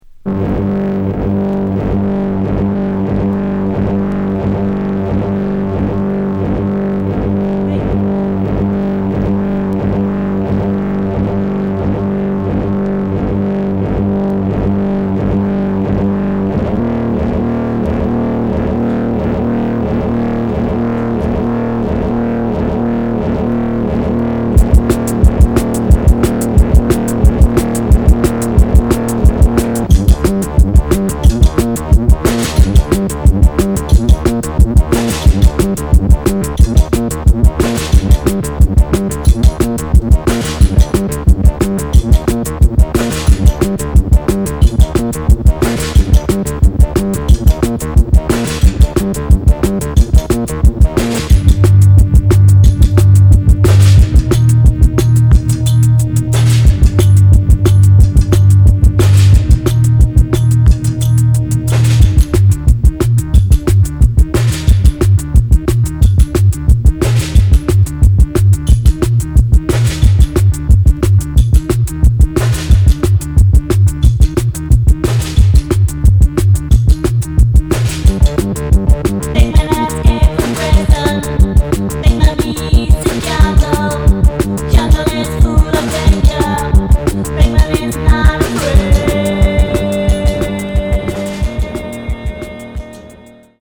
No Waveの行方にも重大な影響を与えたと思われる、強迫的反復で迫ってくるゴリゴリのハードコア逸脱シンセパンク。
キーワード：ミニマル　乙女　宅録　脱線パンク